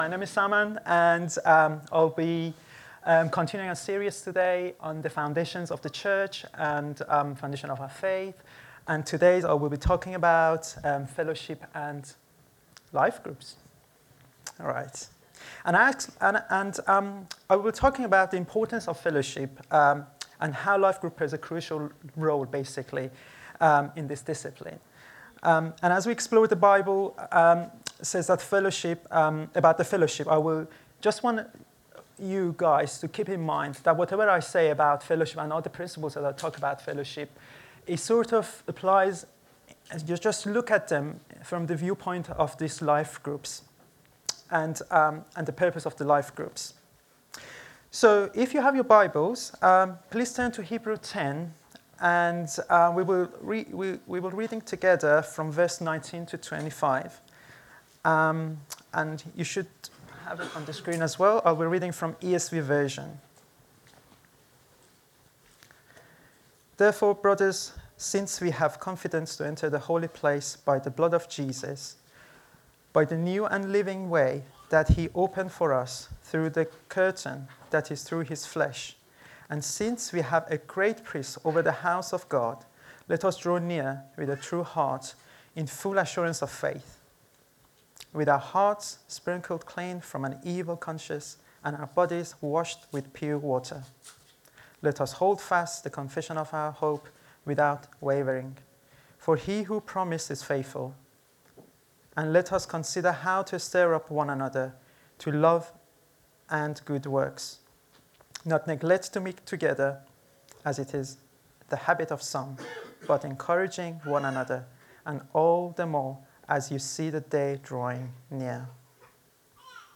Download Fellowship and Life Groups | Sermons at Trinity Church